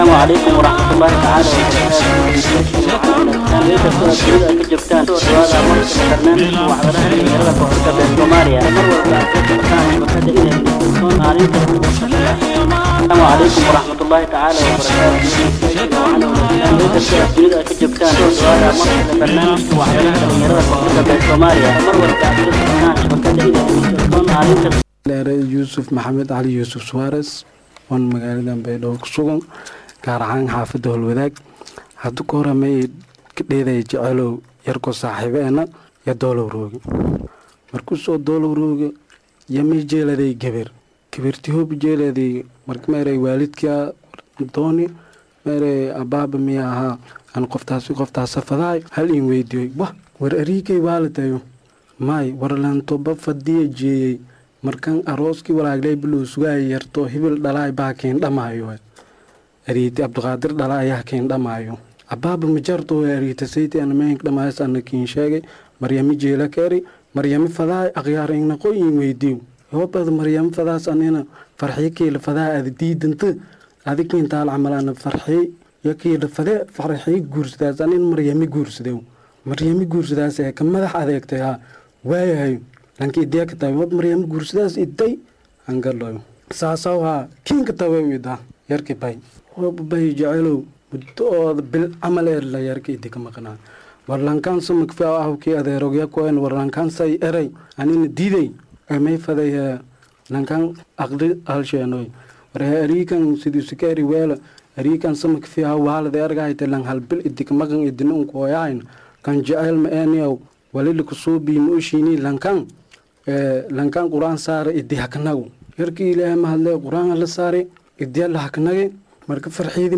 Barnaaijka waxaan maanta ku soo qaadan doona, Wareysiyo la xariira arimaha Jecelka, Riwaayad iyo Sheekooyin kale oo Xiiso badan leh kuwaasoo aad Xamaasad Badan.